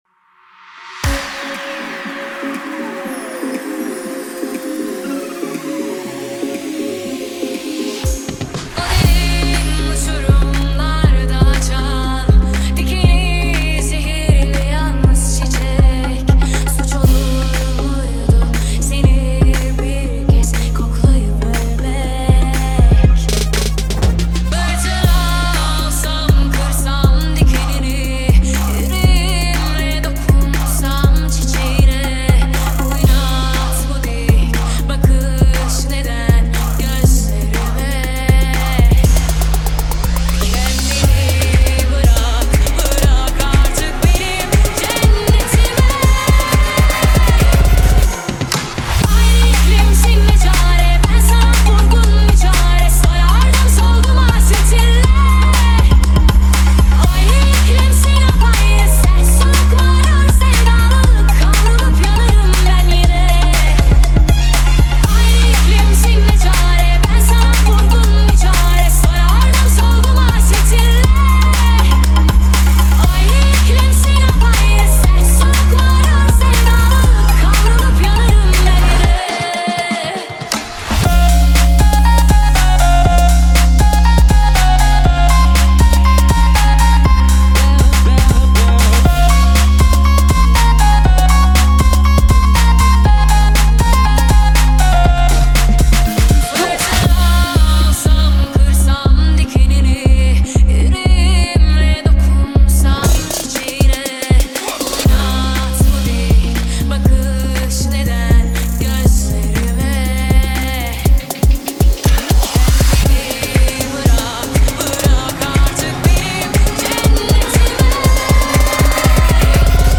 رقص
اهنگ شاد
ریمیکس شاد